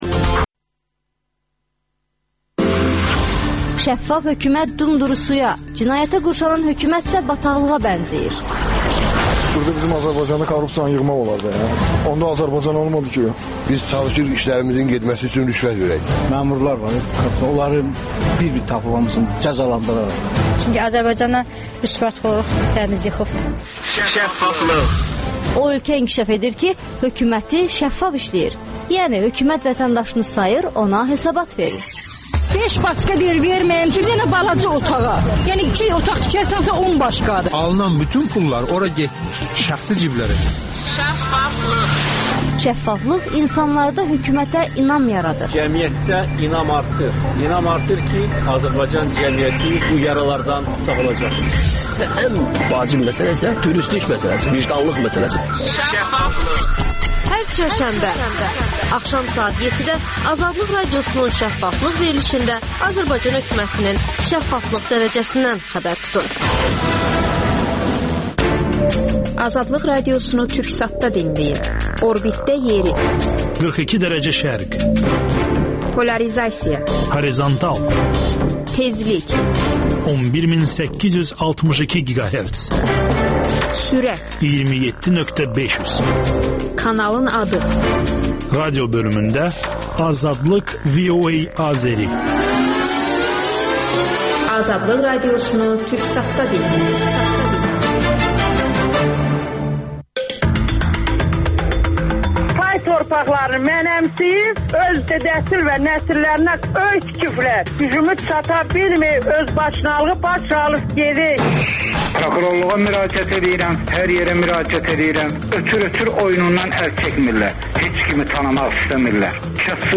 AzadlıqRadiosunun müxbirləri ölkə və dünyada baş verən bu və başqa olaylardan canlı efirdə söz açırlar.